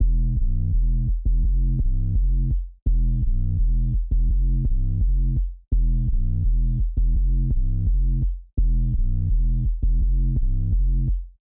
AV_Skate_Bass_168bpm_Amin
AV_Skate_Bass_168bpm_Amin.wav